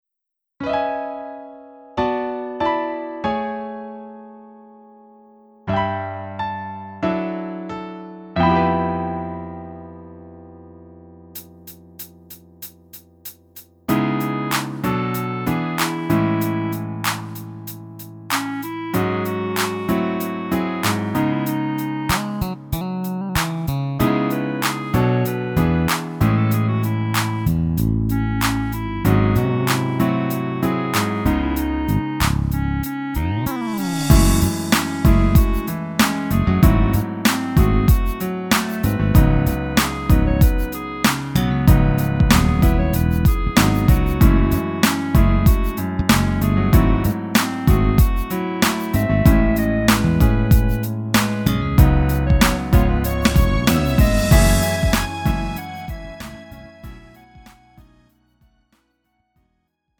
음정 -1키 3:19
장르 가요 구분 Lite MR